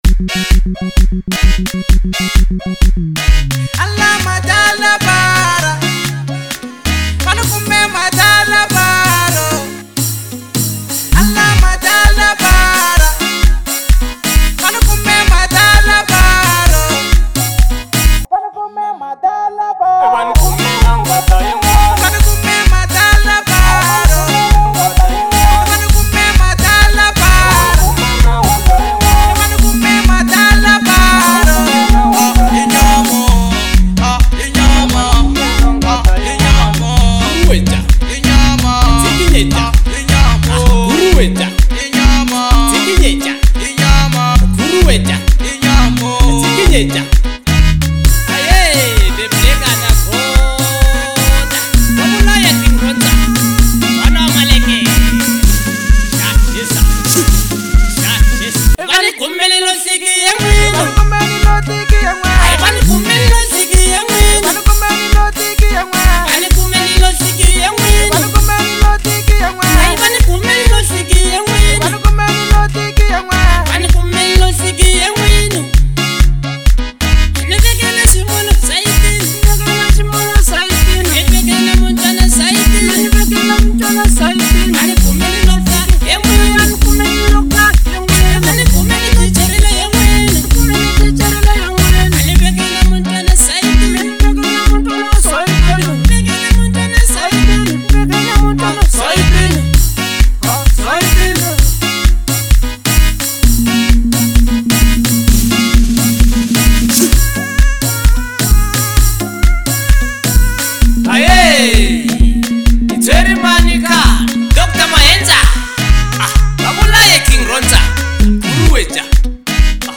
Genre : Local House